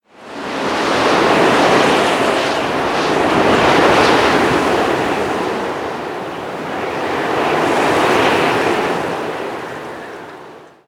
Звуки ветра. Sounds of wind.
Звук резкие ворывы снежной бури.